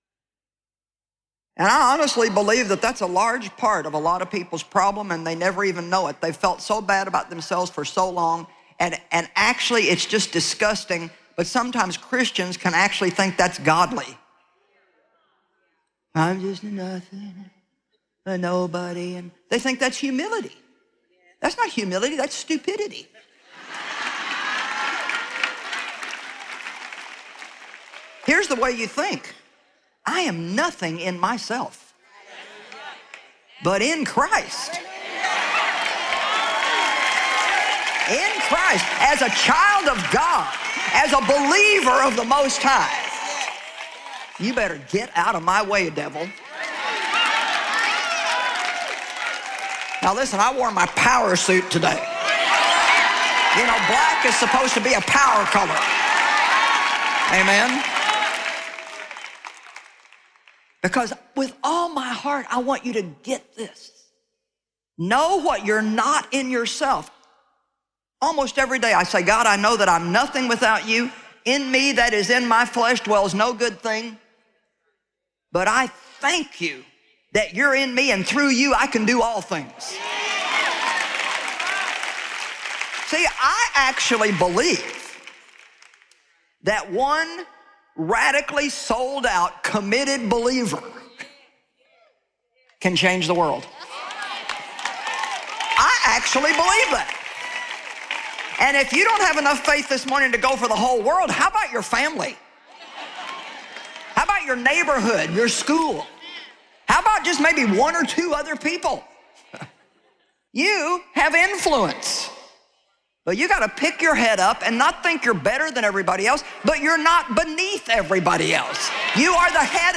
7 Ways to Increase Your Happiness Audiobook
Narrator